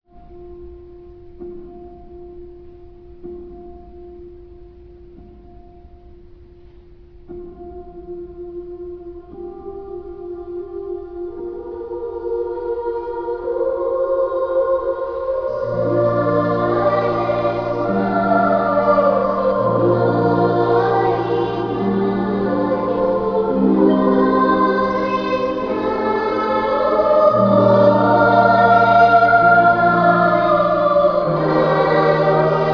boy soprano
harp.